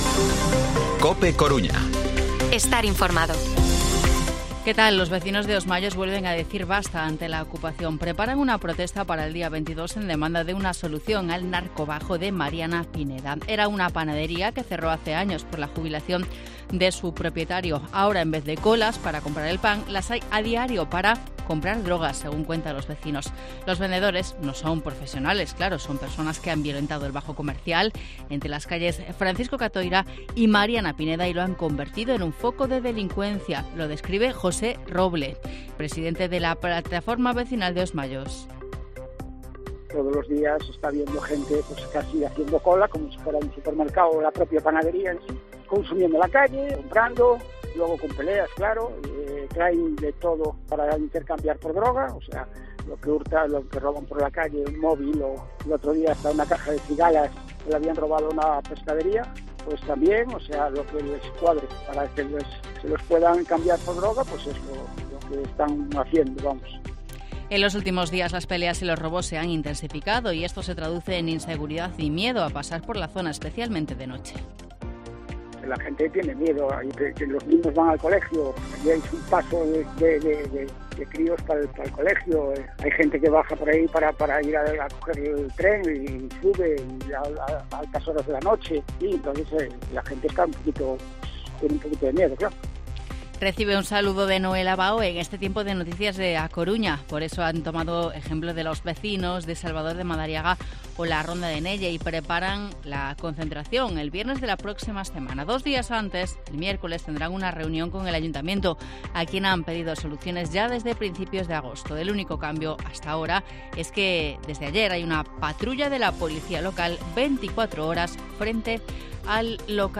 Informativo Mediodía COPE Coruña miércoles, 13 de septiembre de 2023 14:20-14:30